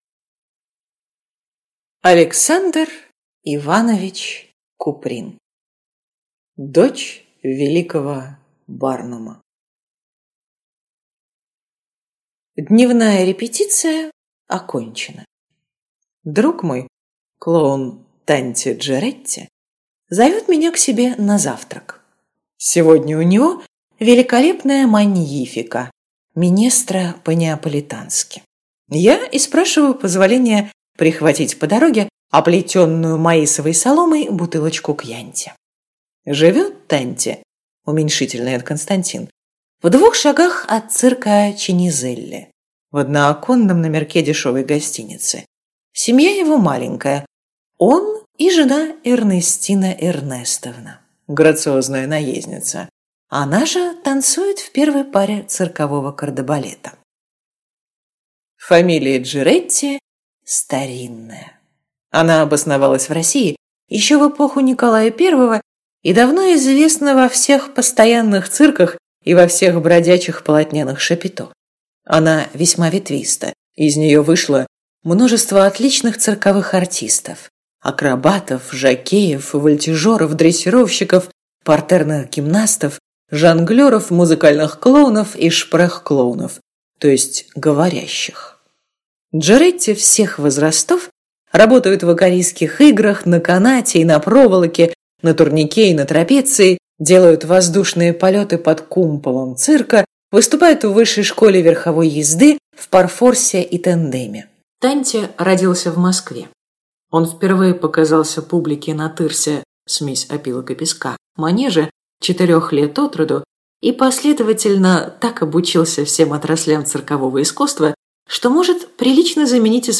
Аудиокнига Дочь великого Барнума | Библиотека аудиокниг